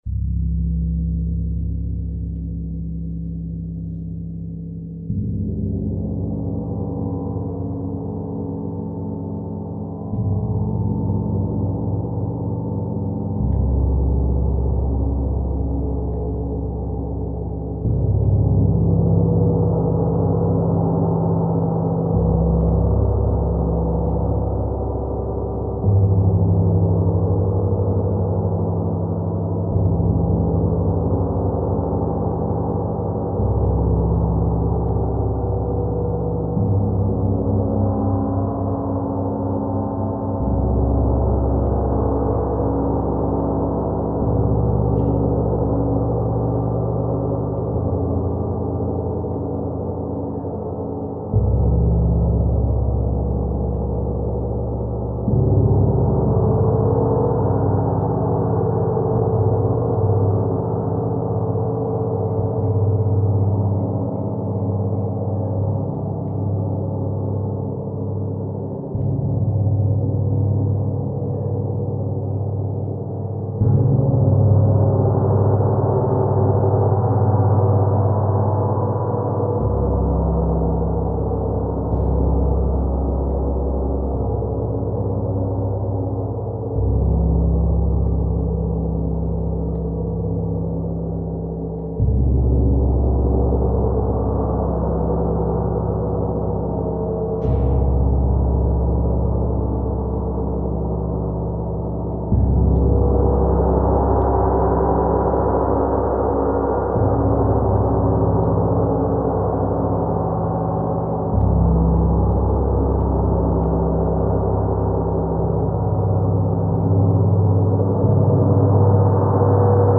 Esta grabación es la real del Gong disponible
Gong Sinfónico 85cm